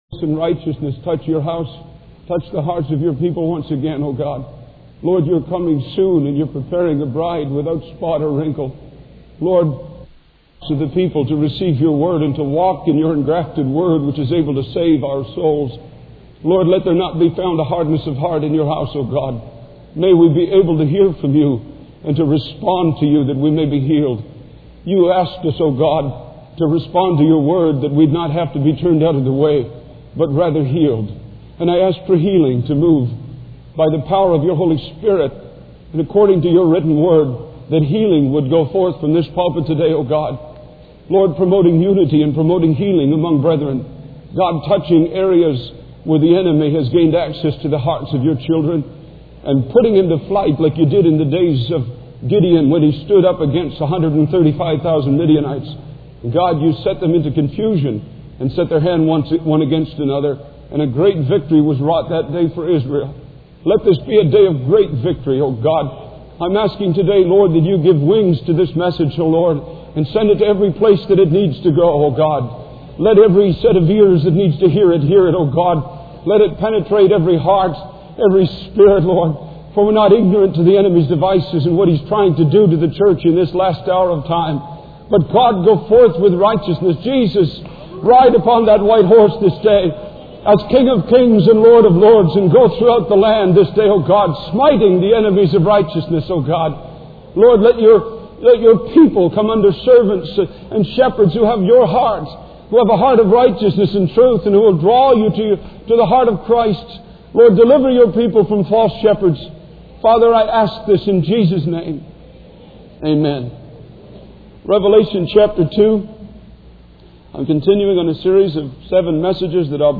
In this sermon, the preacher emphasizes the importance of seeking God's approval in every aspect of our lives. He warns against false teachings and false promises of freedom, stating that those who promote such things are actually slaves to corruption.